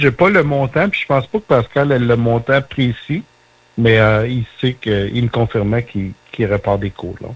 Le député Donald Martel en a fait l’annonce vendredi matin sur les ondes du VIA 90.5 FM.